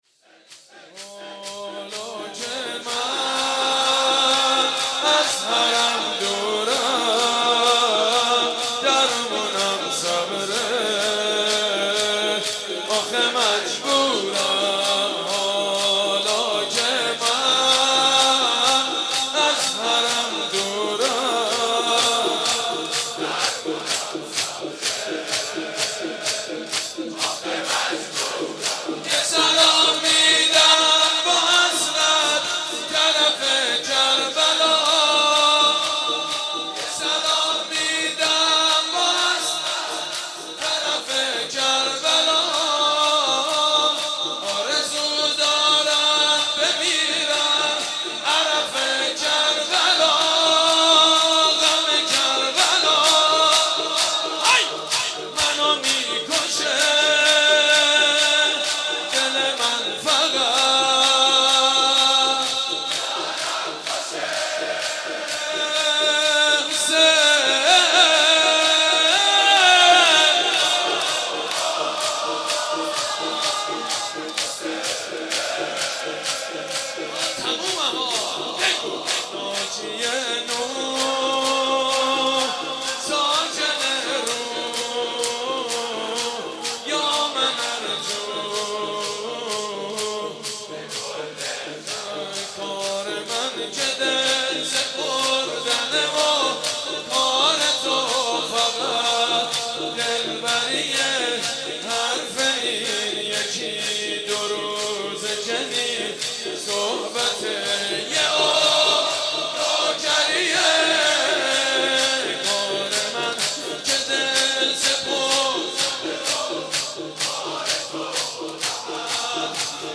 مداحی شنیدنی
در شب شهادت حضرت مسلم بن عقیل (ع) امسال